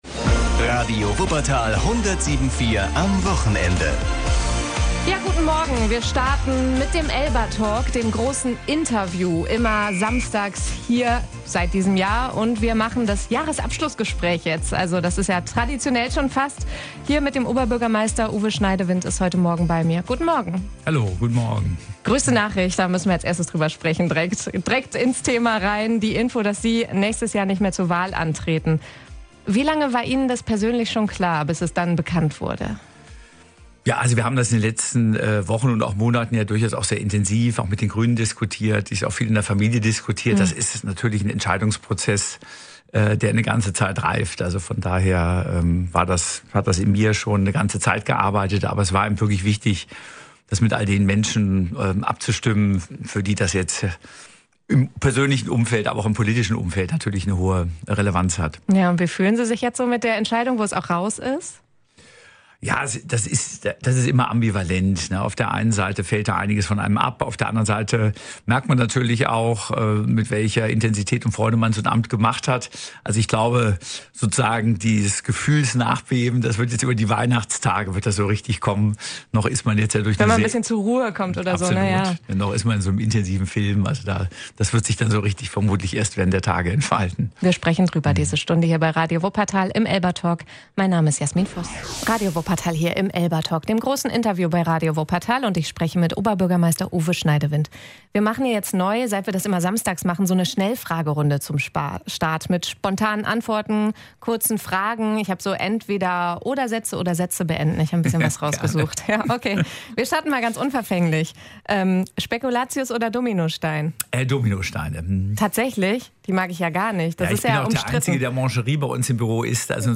Traditionell kommt der Oberbürgermeister zu uns in den letzten ELBA-Talk des Jahres.